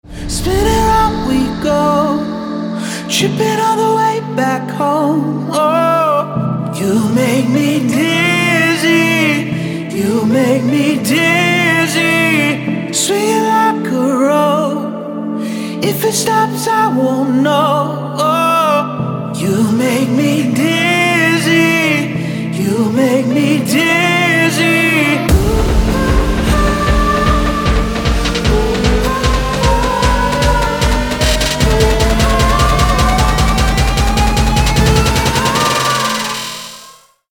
• Качество: 224, Stereo
поп
мужской вокал
Melodic